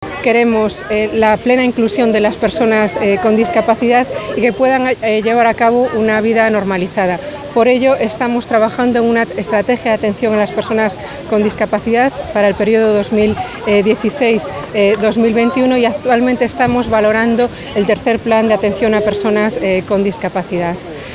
En una intervención ante los medios en este parque de ocio, Prado explicó que se contabilizan ya 322.327 madrileños con discapacidad igual o superior al 33%, lo que supone el 5,01% de la población total de la región, con un incremento del 4,7% respecto al año anterior.